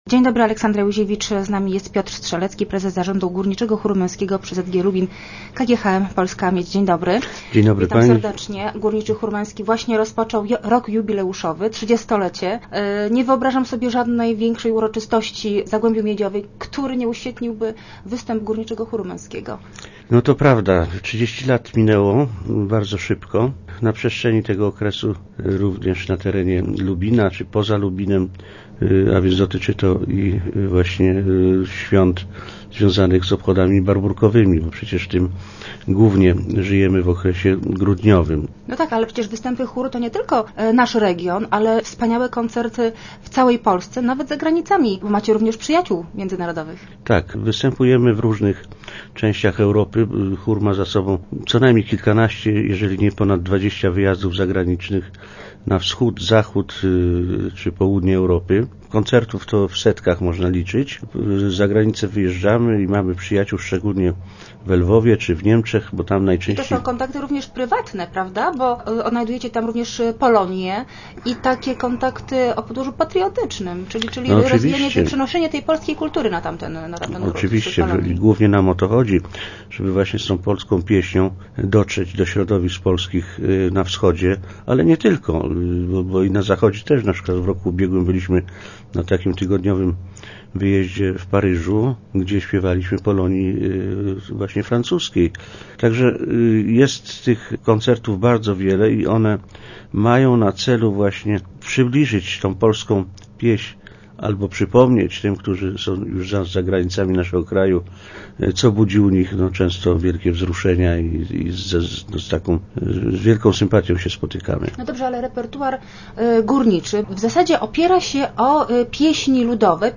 Rozmowy Elki